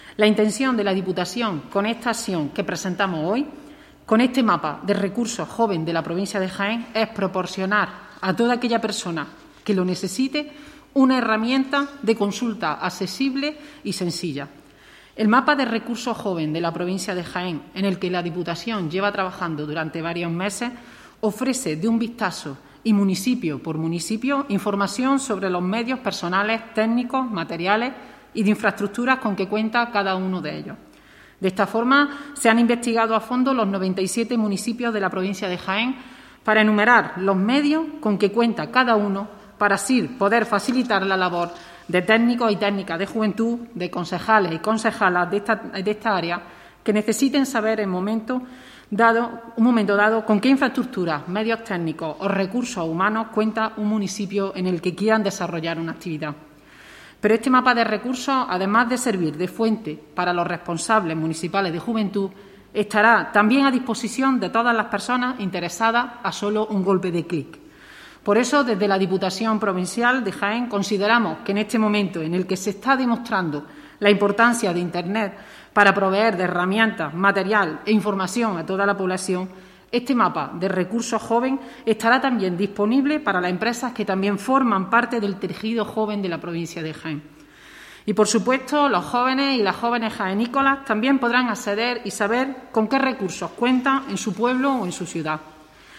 La diputada de juventud, Pilar Lara, presenta el Mapa de Recursos de la Provincia de Jaén.